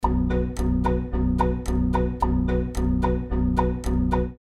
• Качество: 320, Stereo
Electronic
без слов